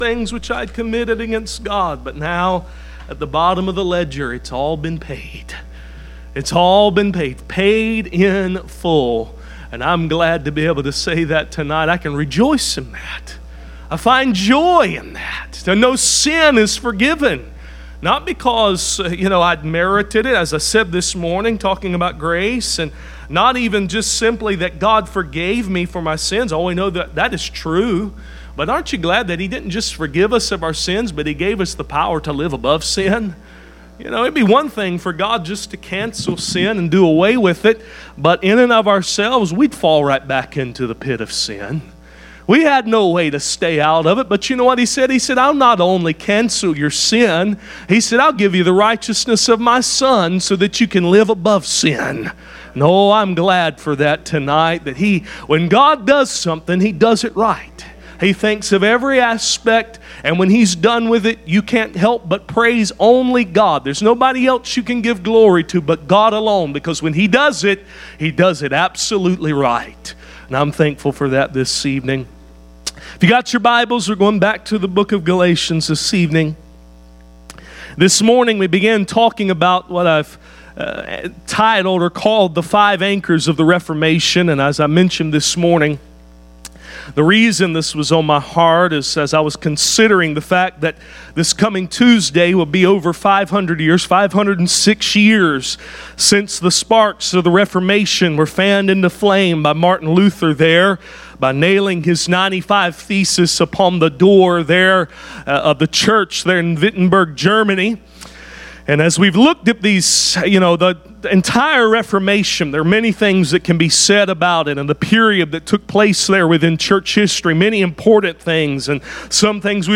Galatians Service Type: Sunday Evening « Five Anchors in Galatians